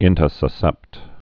(ĭntə-sə-sĕpt)